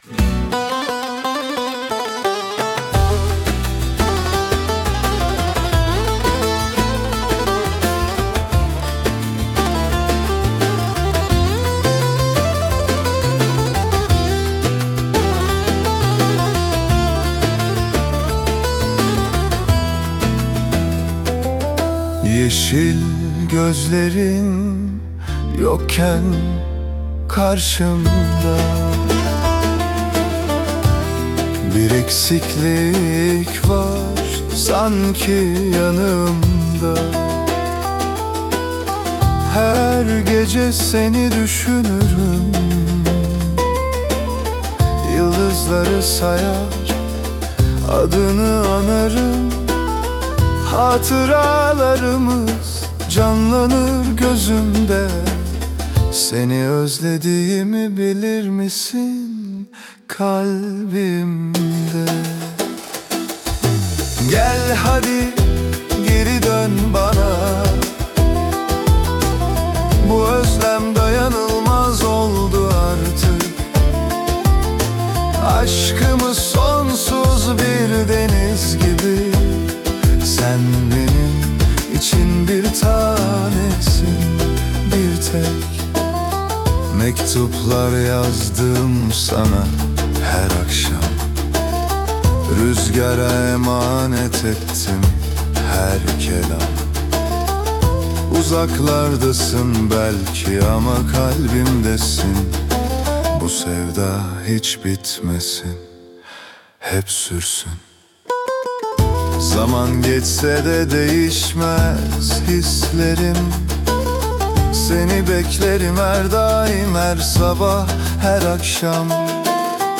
AI ile üretilen 58+ özgün müziği keşfedin
🎤 Vokalli 02.12.2025